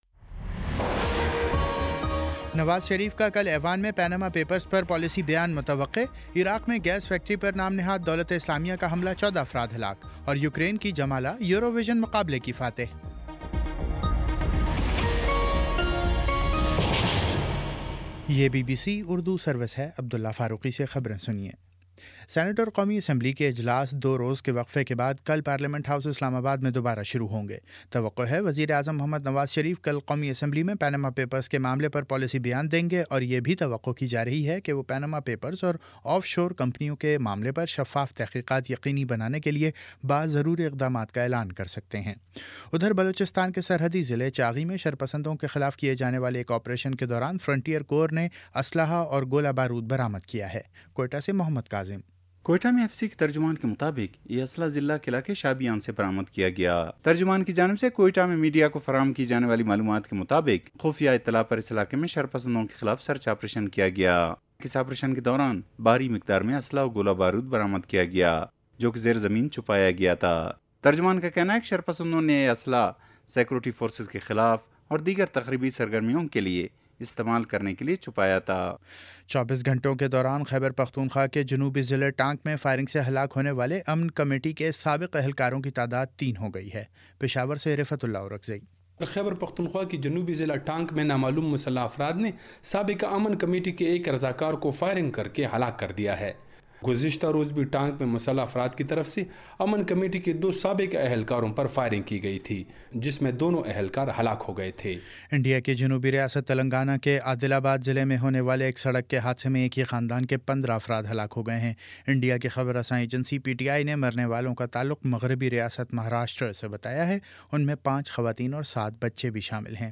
مئی 15 : شام چھ بجے کا نیوز بُلیٹن